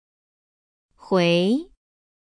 huí